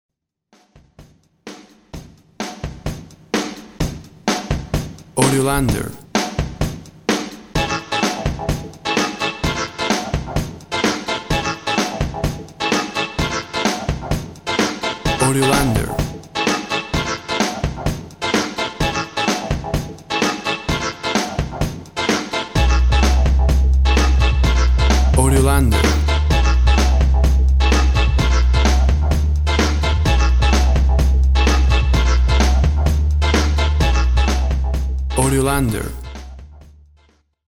Intense, corporate music.
Tempo (BPM) 128